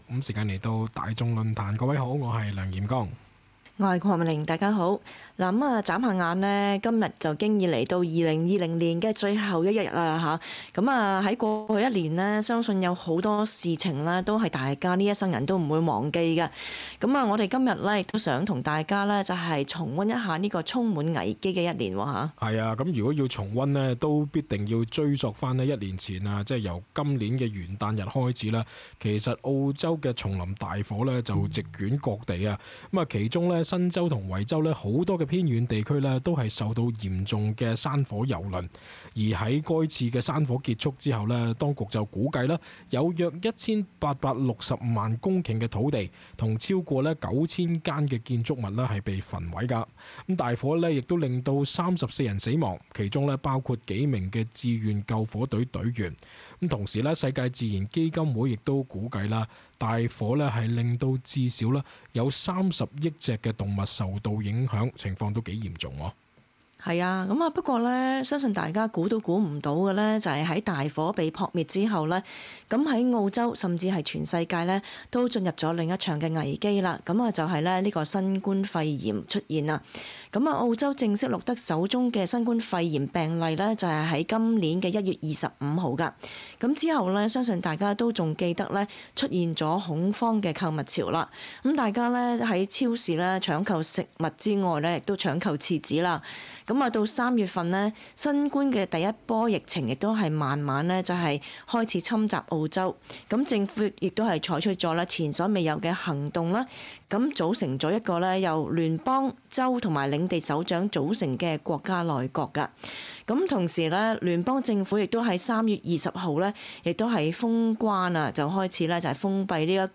talkback_-_yearend_wishes_-_dec_31.mp3